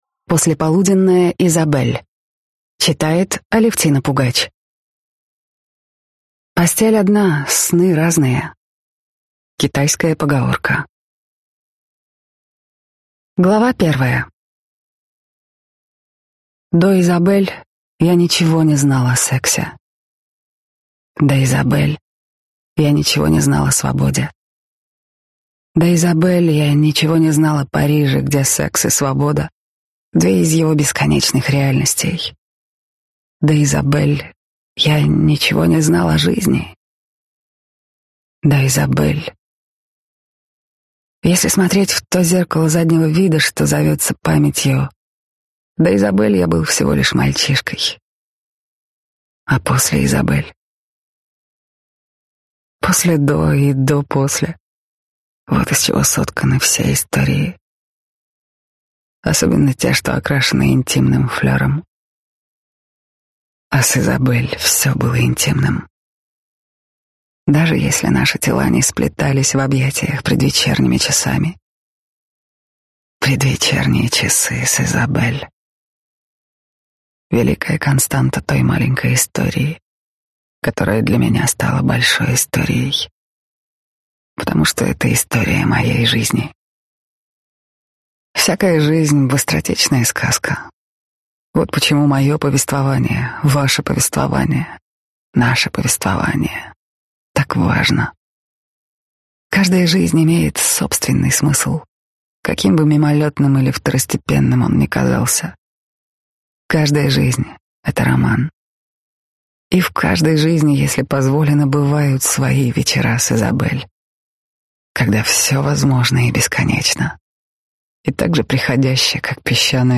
Аудиокнига Послеполуденная Изабелла | Библиотека аудиокниг